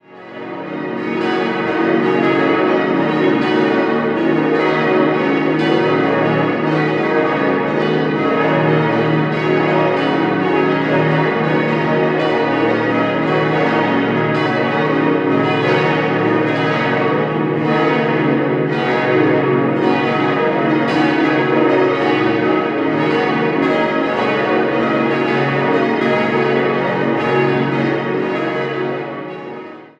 Die Reformierte Kirche am Rand der Altstadt wurde 1968/69 nach den Plänen des Zürcher Architekten Benedikt Huber errichtet. 6-stimmiges Geläut: b°-des'-es'-ges'-as'-b' Die Glocken wurden 1968 bei der Gießerei Rüetschi in Aarau gefertigt.